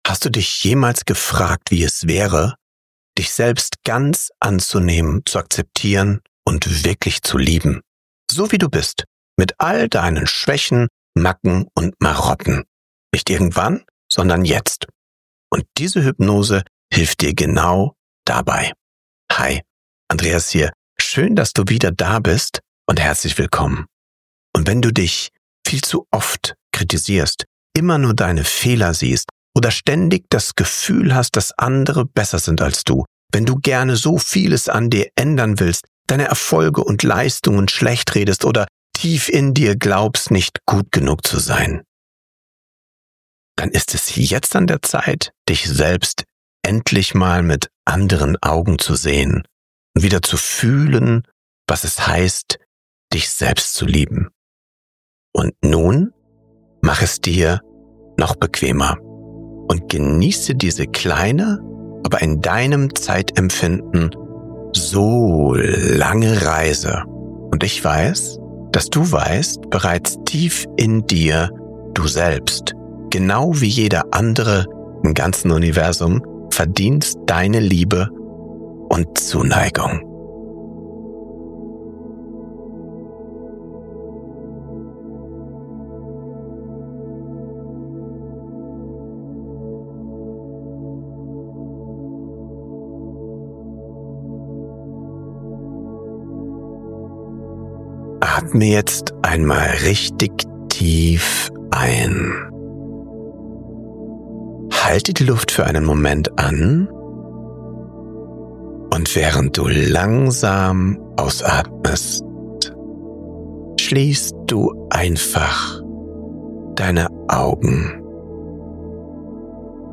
Diese Hypnose begleitet Dich in den Schlaf – und zurück zu dir selbst. Sie ist eine liebevolle Einladung an dein Unterbewusstsein, deinen Selbstwert zu erinnern und Dein Herz zu heilen.